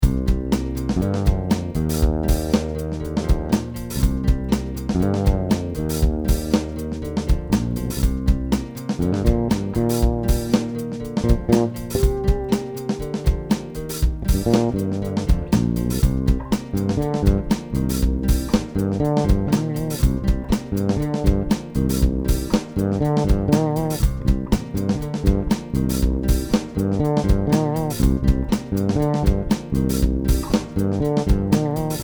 Fender HGT Jaco 62 Reissue MIJ with an Audere JZ3